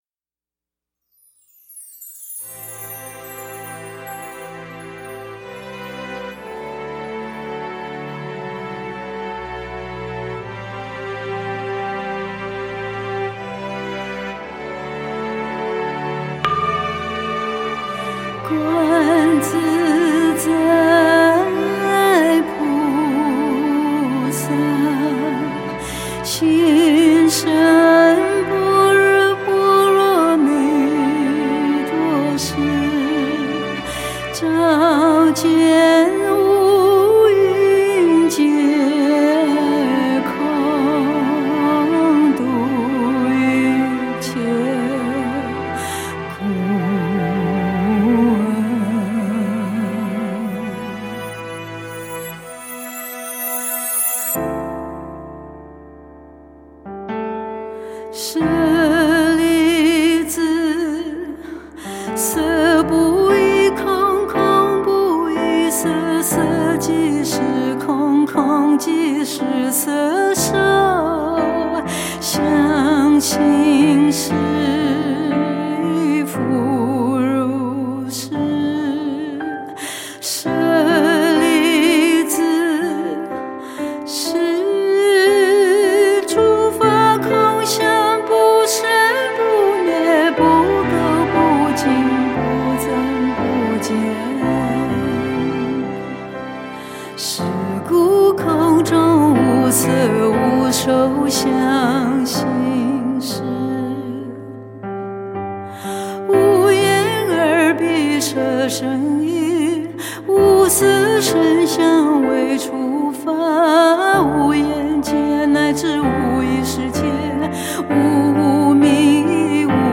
佛音 诵经 佛教音乐